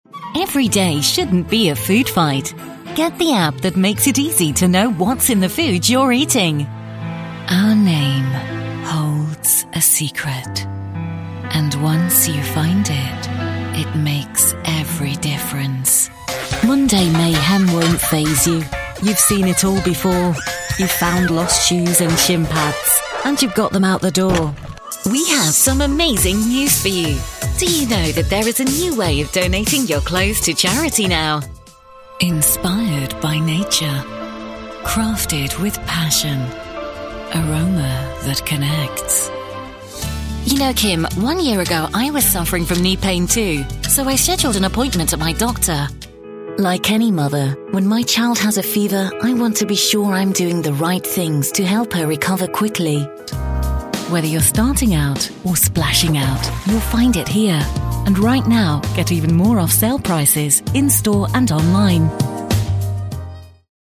Anglais (britannique)
Chaleureux
Polyvalent
De la conversation